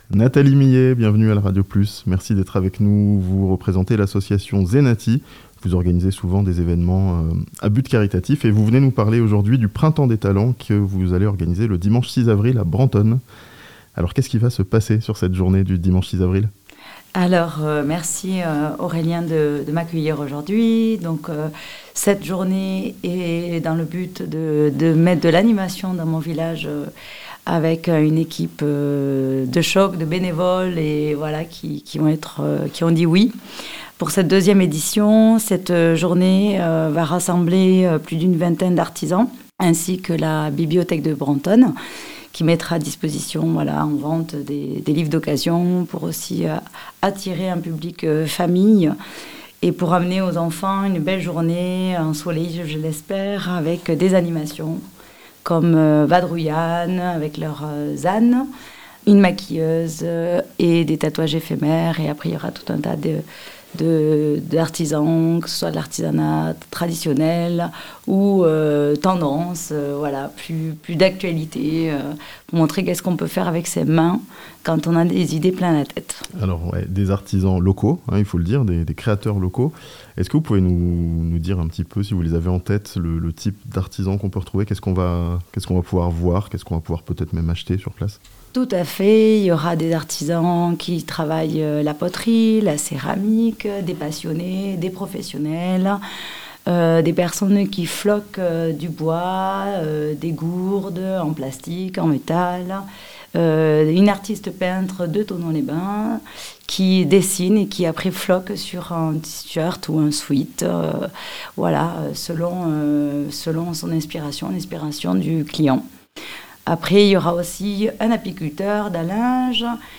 Les talents locaux mis en lumière à Brenthonne le 6 avril (interview)